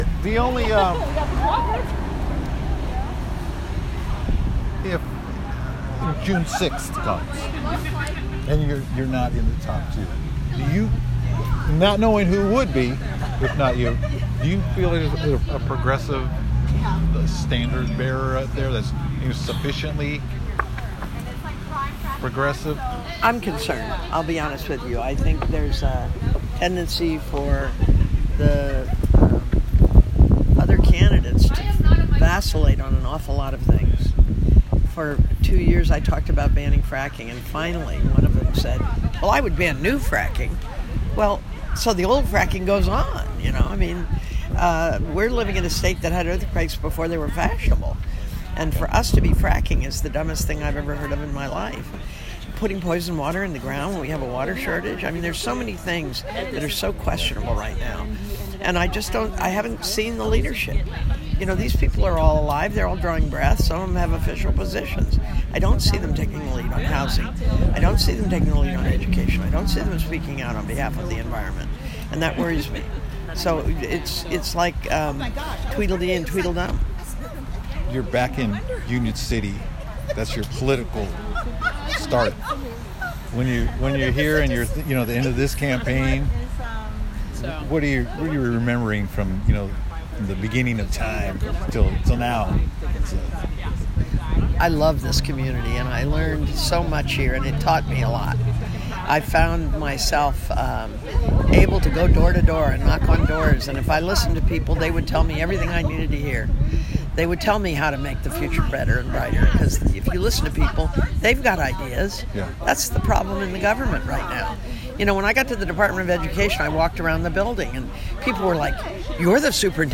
delaine-eastin-union-city-interview.m4a